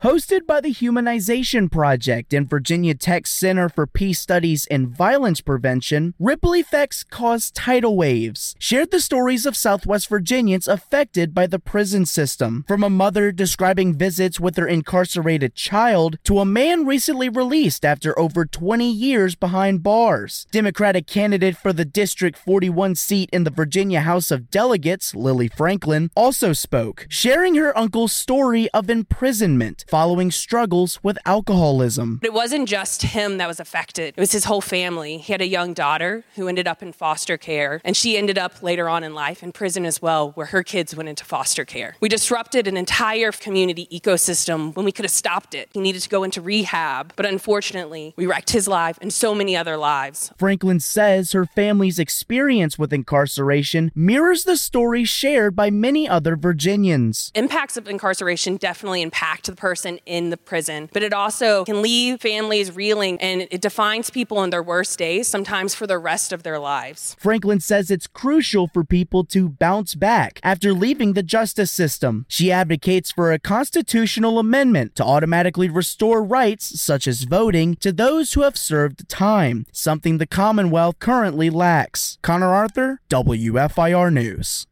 Recently at the Lyric Theatre in Blacksburg, people shared how incarceration shaped their lives and families.